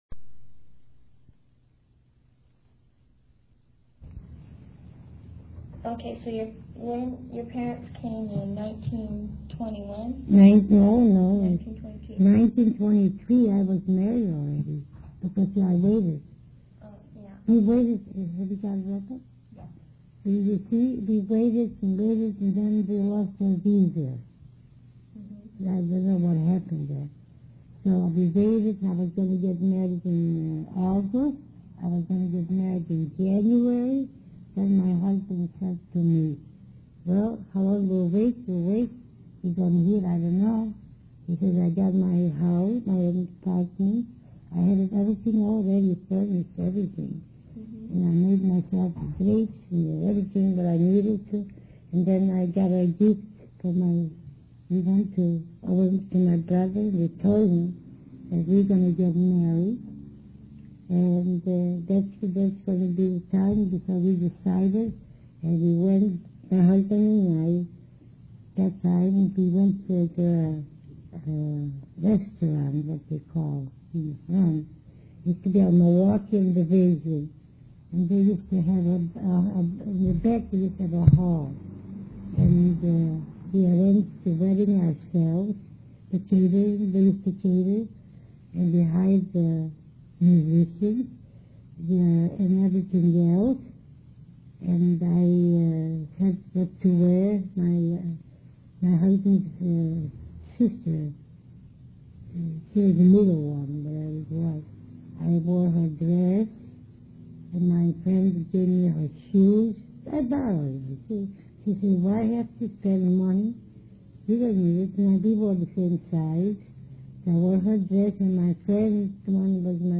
audio interview #1 of 3